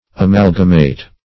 Amalgamate \A*mal"ga*mate\, v. i.